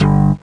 cch_bass_one_shot_upright_G.wav